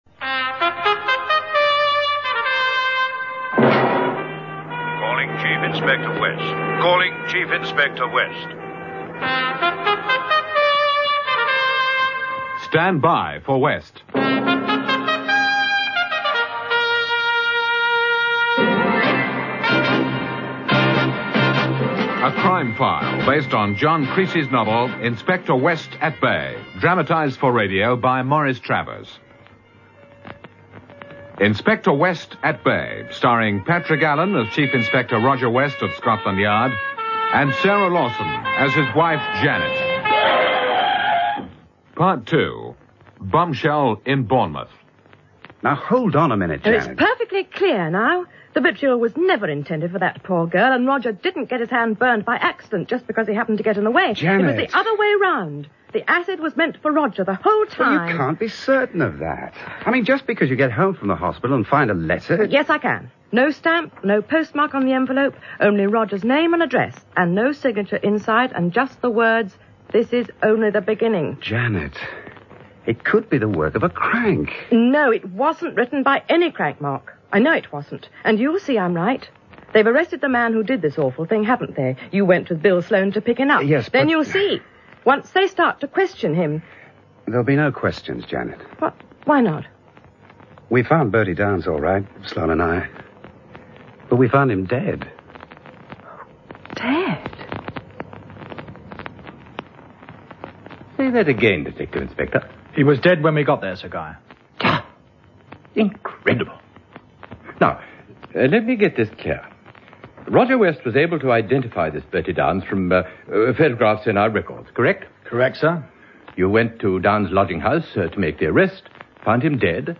Politie (BBC – Engelstalig): 8 delen Totale duur: ongeveer 3 uur en 35 minuten